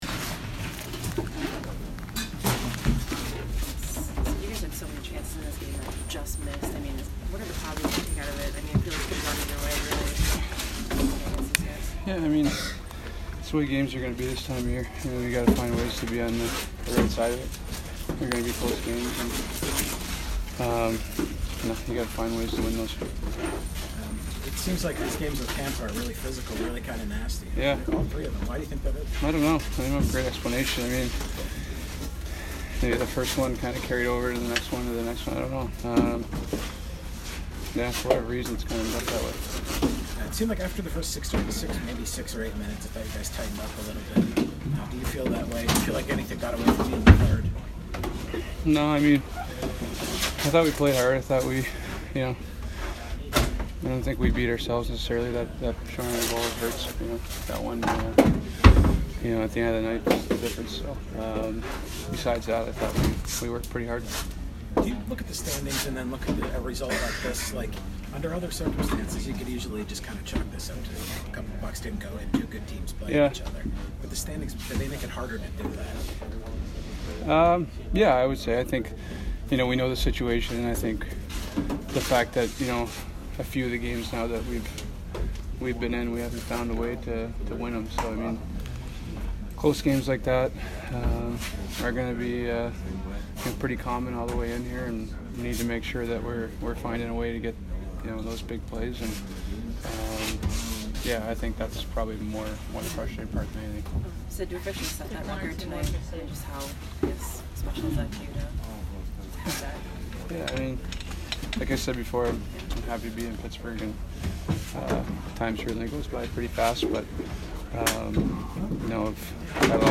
Sidney Crosby post-game 2/9